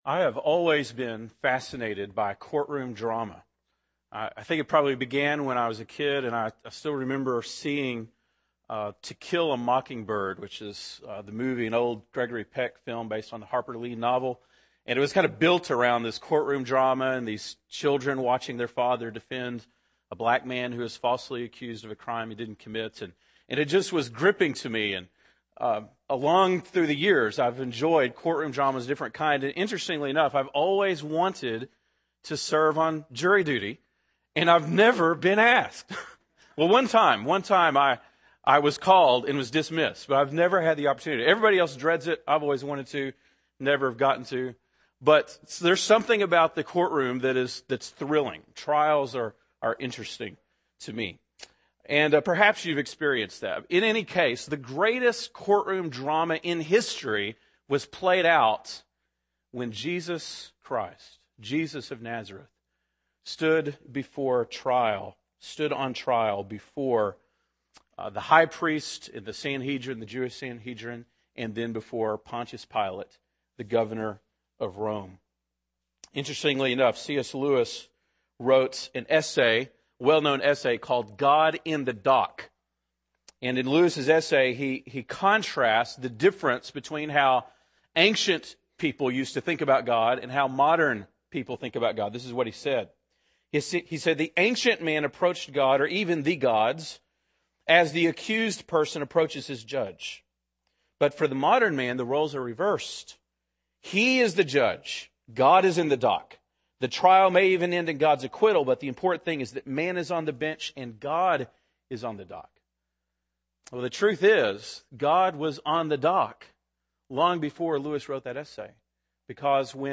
March 22, 2009 (Sunday Morning)